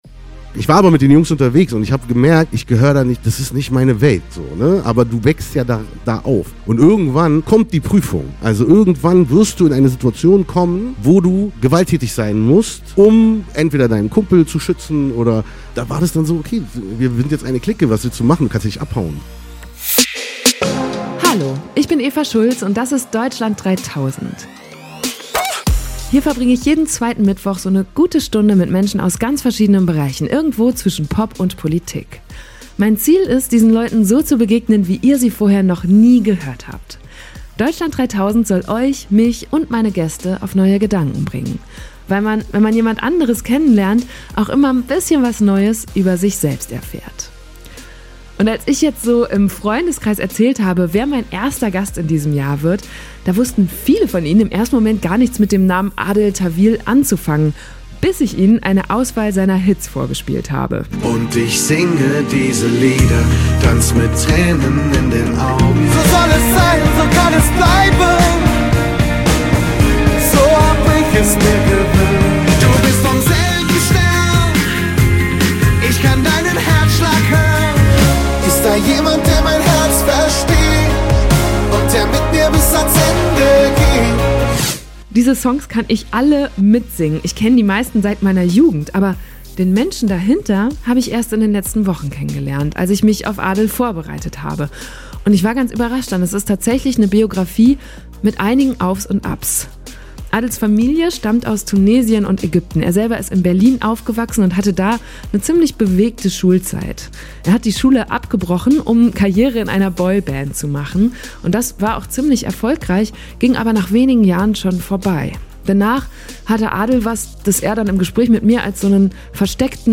Über all das haben wir gesprochen und ich glaube, Adel hat auch deshalb so offen erzählt und aufgemacht, weil es eine Live-Aufzeichnung war. Wir waren in Mannheim beim SWR Podcastfestival vor über 250 Gästen.